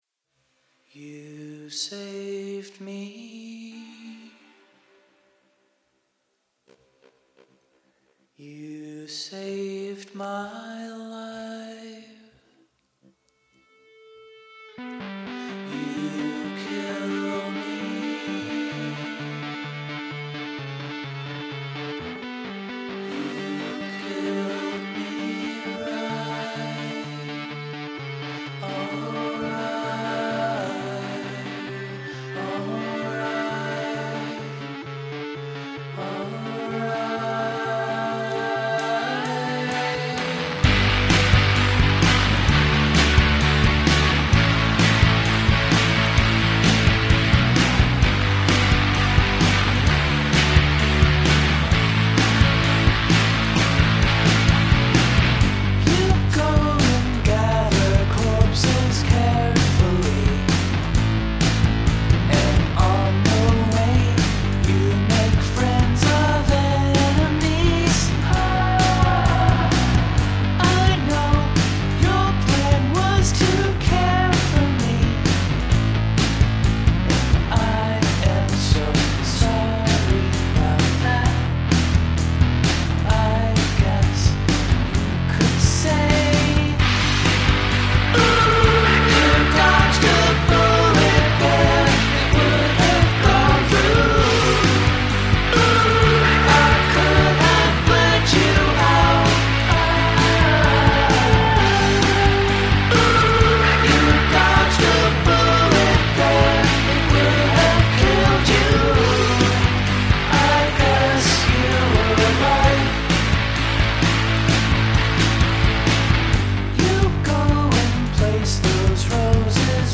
First, the crunchy rock song: